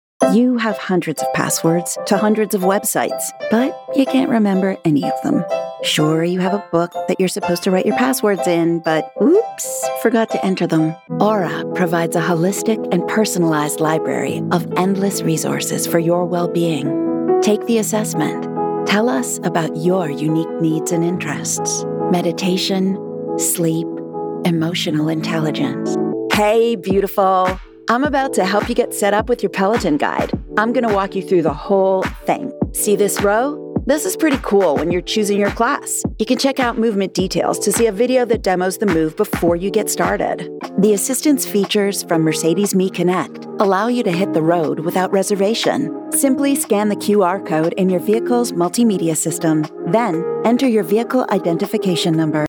Vídeos explicativos
Tengo mi propio estudio con equipos de última generación;
DAW - Protools, Mic - Sennheiser MKH 416, Preamp - Universal Audio 6176, Interfaz - Volt 176.
Mediana edad